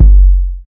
Waka KICK Edited (34).wav